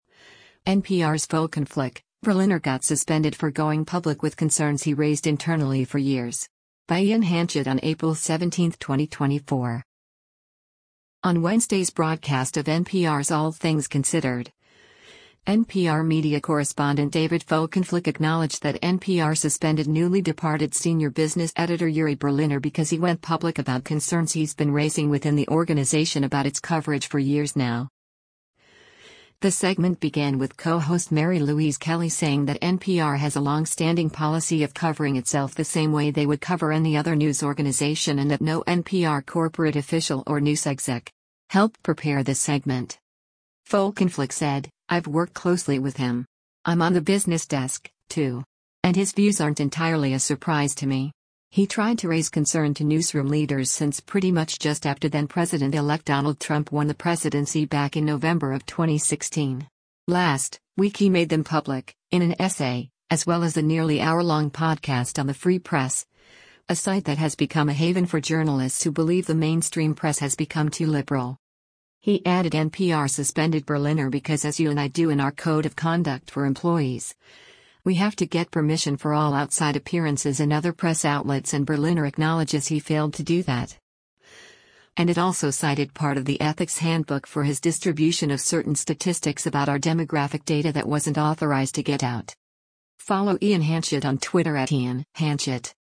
The segment began with co-host Mary Louise Kelly saying that NPR has a “longstanding” policy of covering itself the same way they would cover any other news organization and that “no NPR corporate official or news exec. helped prepare this segment.”